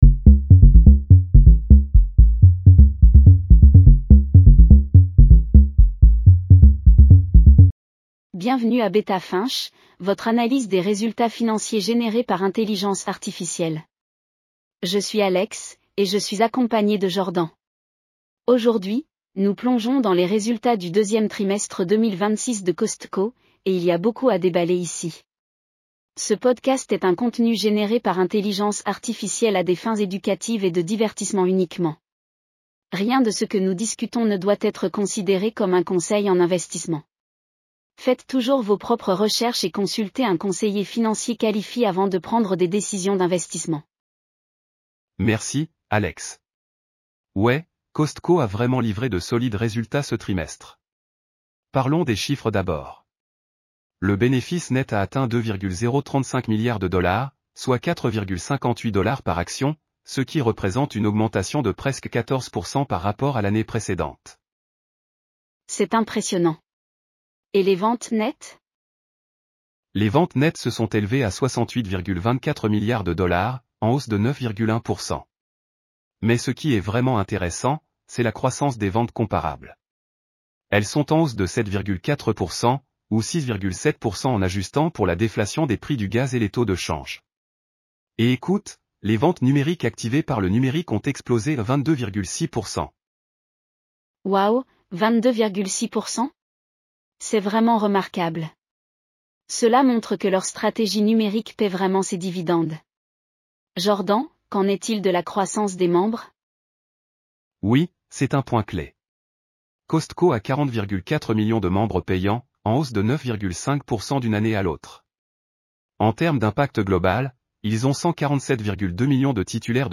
AI-powered earnings call analysis for Costco (COST) Q2 2026 in Français.